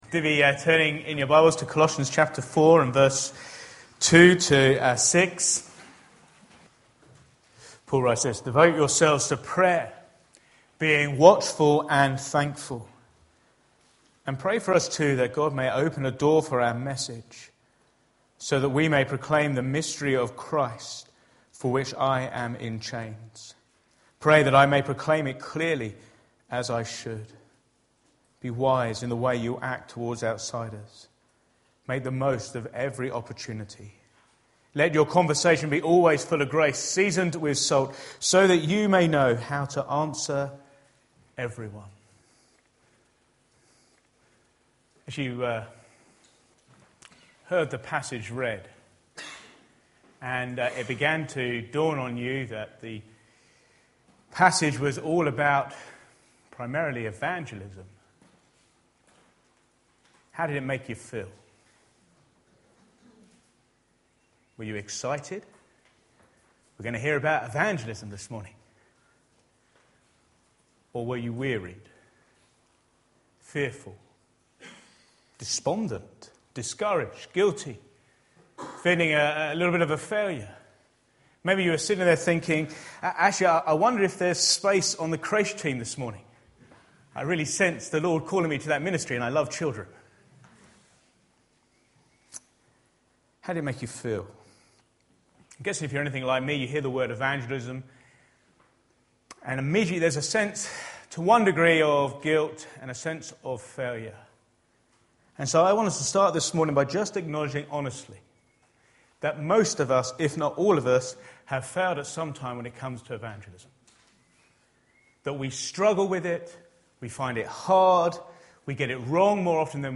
Back to Sermons Praying with One Eye Open